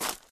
added base steps sounds
ground_1.ogg